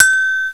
Index of /m8-backup/M8/Samples/Fairlight CMI/IIX/PERCUSN1
NEWBELL.WAV